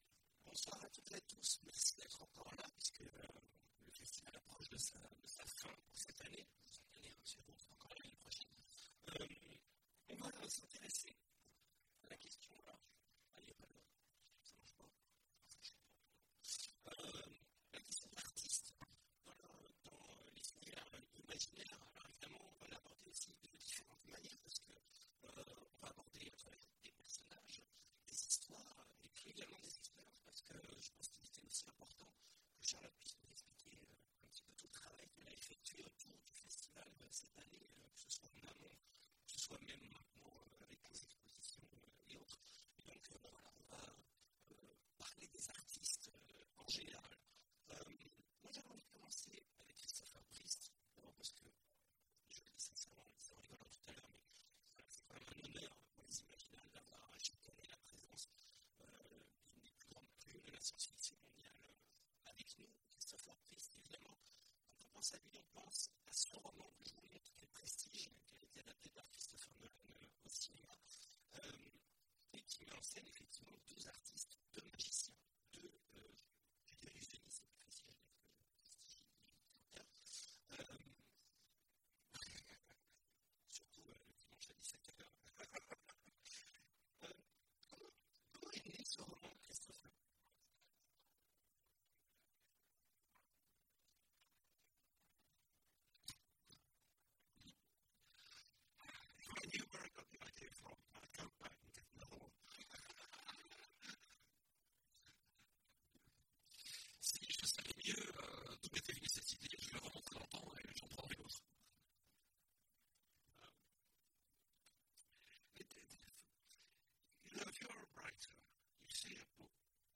Imaginales 2016 : Conférence Créateurs de fiction
Imaginales16CreateurFiction.mp3